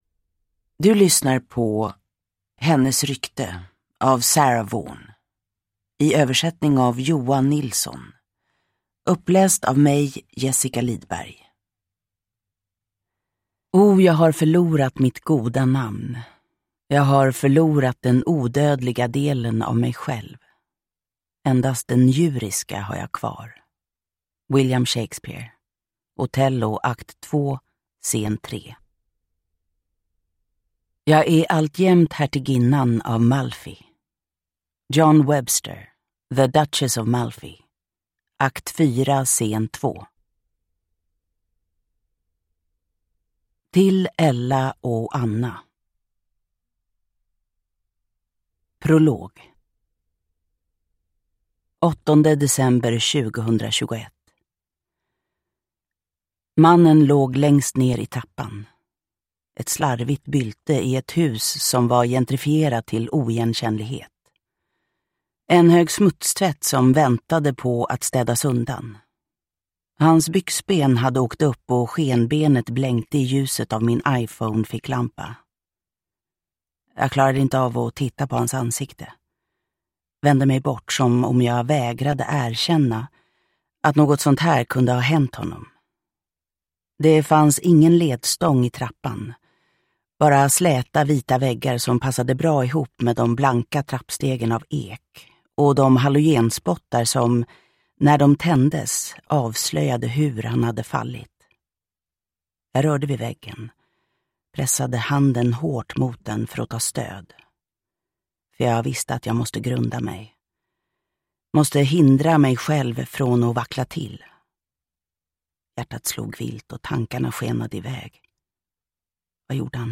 Hennes rykte – Ljudbok – Laddas ner
Uppläsare: Jessica Liedberg